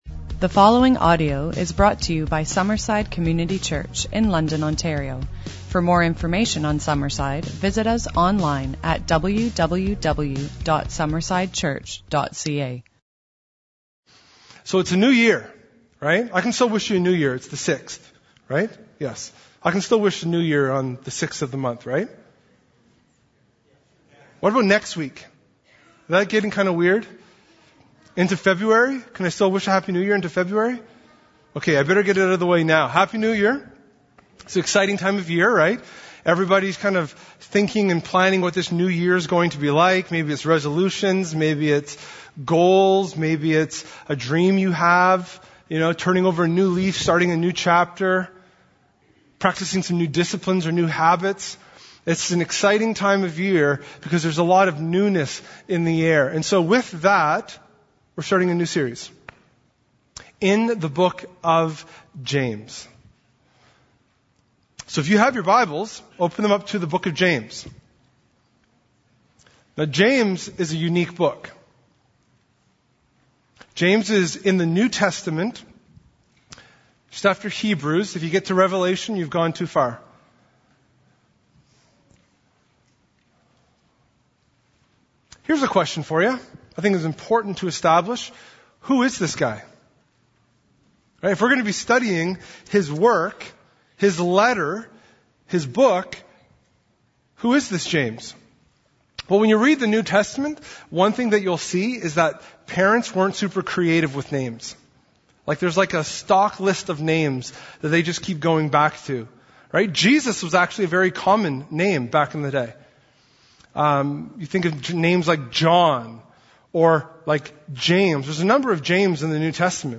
In the kick off to a new sermon series we’re tackling a universal issue that we all face: dealing with the hard stuff of life.